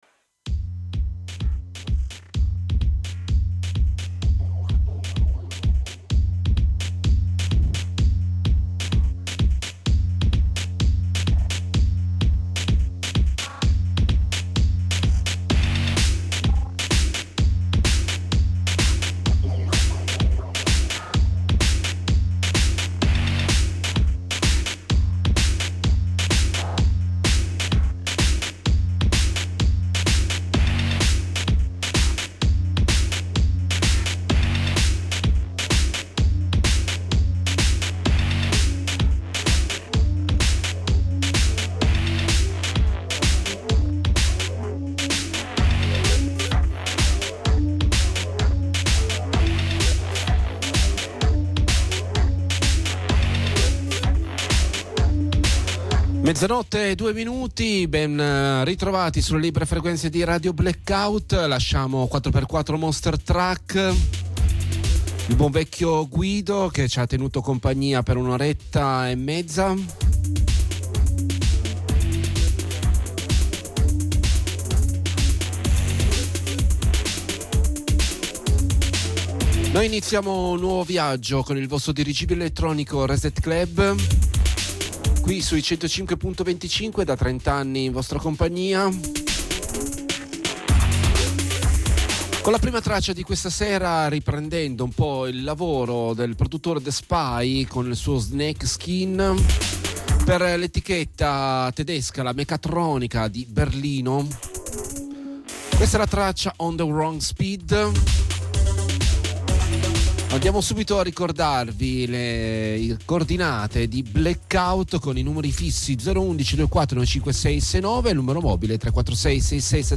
I suoni iniziali Electro e poi a salire la Techno.